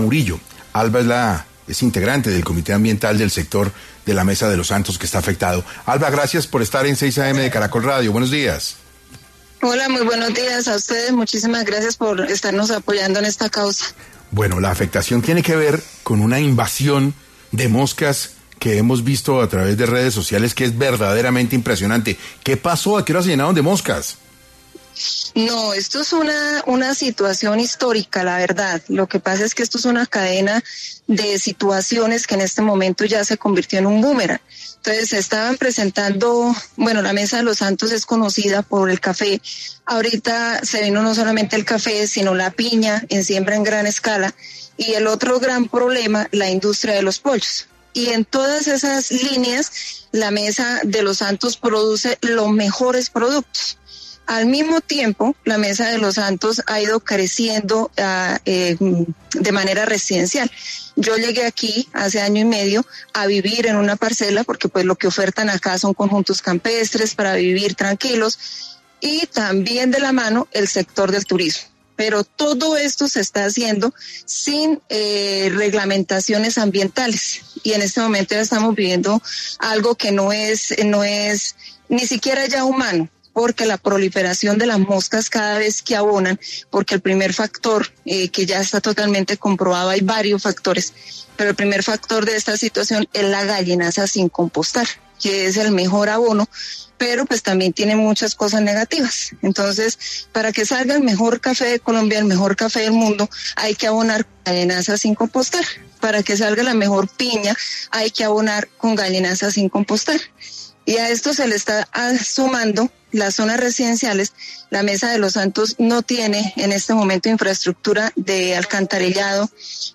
En diálogo con 6AM de Caracol Radio